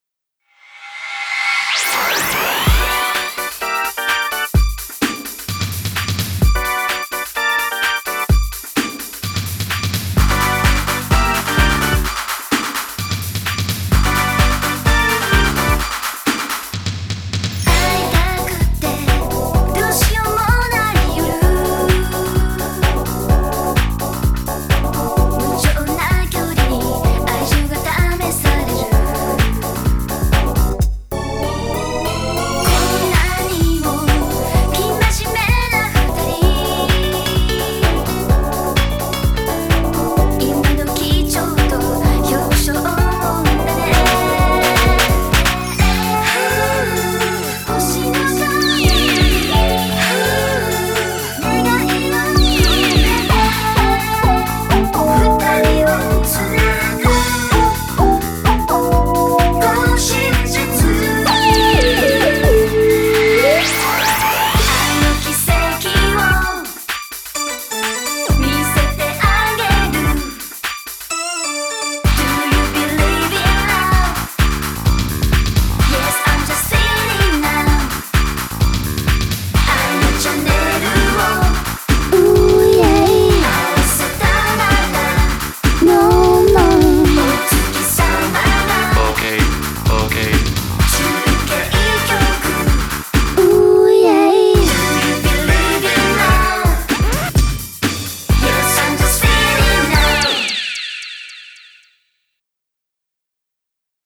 BPM128
Audio QualityPerfect (High Quality)
Genre: J-GARAGE POP.